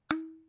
描述：记录的刀片声音。
标签： 刀片声 单击 叩诊 记录 毛刺 叶片 振动 现场录音 拍摄 声音
声道立体声